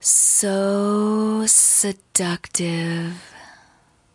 用AT2020话筒录入Apogee Duet，用Record.
标签： 美国 英国 女性 女孩 请求 性感 说话 说话 声带 声音 女人
声道立体声